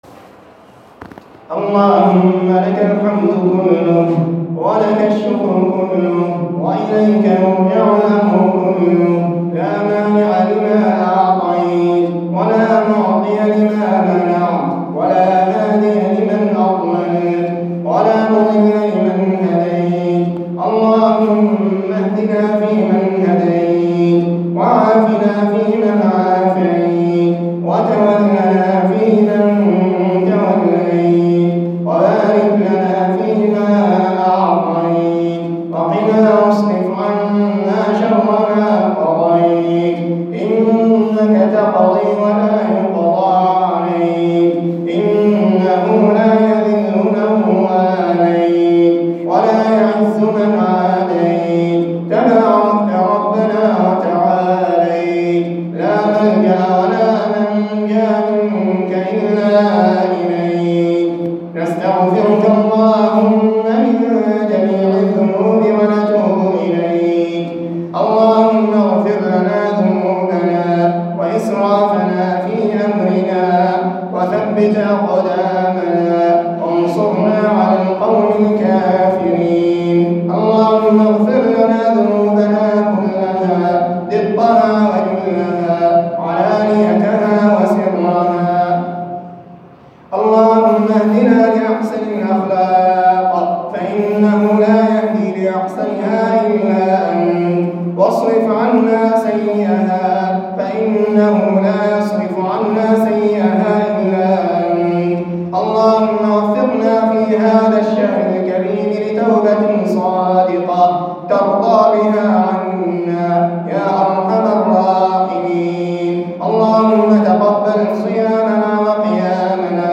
دعاء خاشع من صلاة التراويح
تسجيل لدعاء خاشع ومؤثر من صلاة التراويح